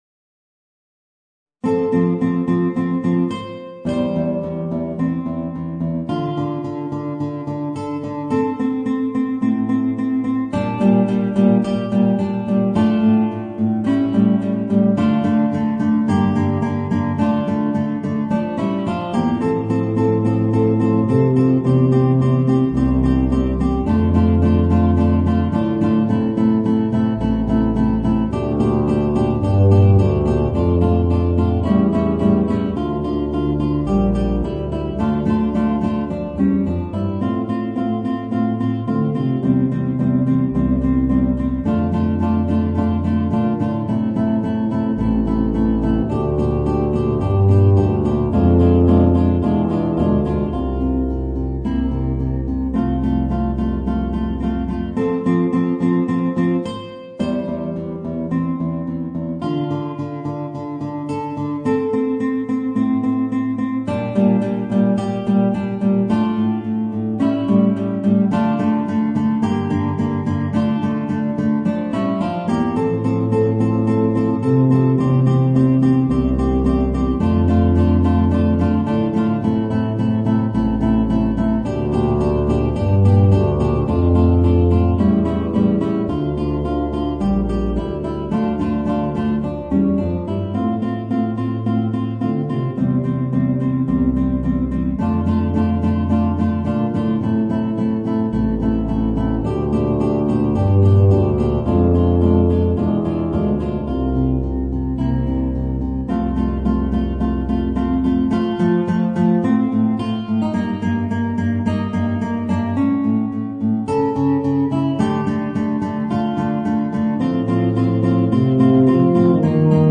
Voicing: Guitar and Tuba